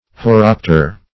horopter - definition of horopter - synonyms, pronunciation, spelling from Free Dictionary
Horopter \Ho*rop"ter\, n. [Gr. ? boundary + ? one who looks.]